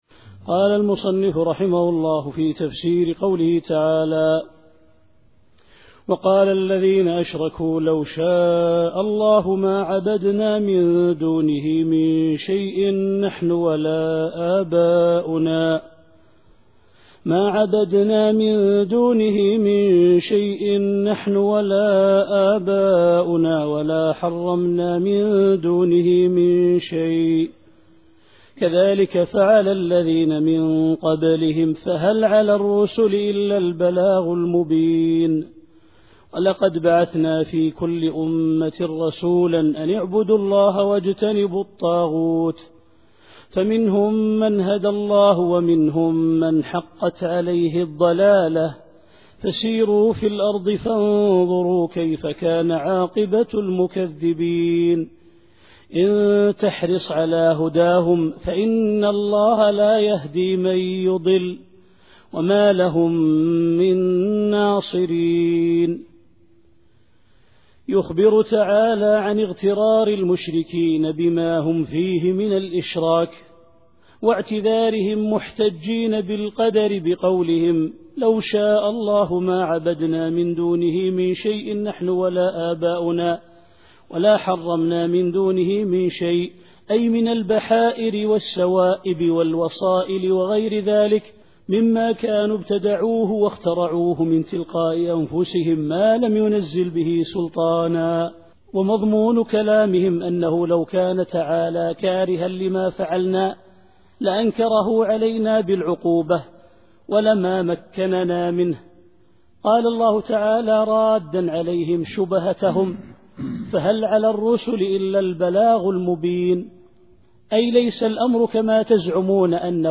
التفسير الصوتي [النحل / 35]